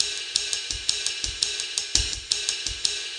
RIDE_LOOP_7.wav